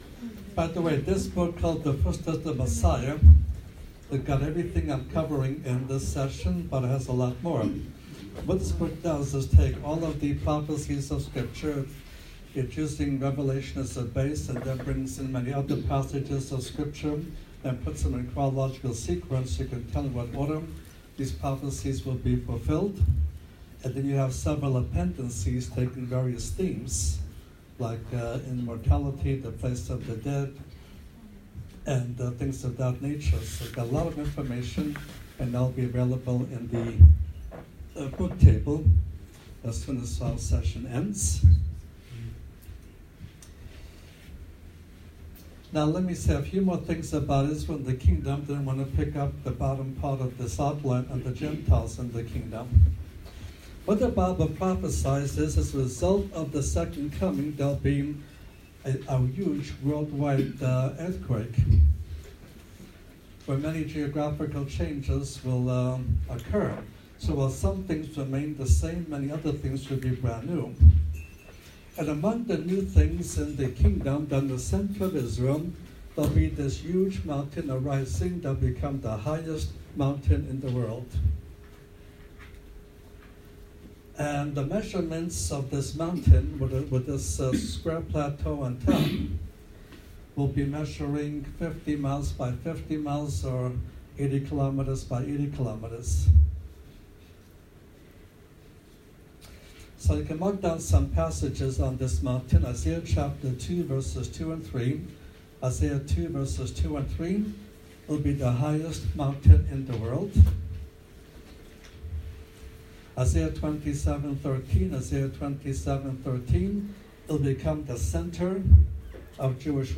God, Lord God Most High, Bible, Christian, Christianity, Jesus Christ, Jesus, salvation, good news, gospel, messages, sermons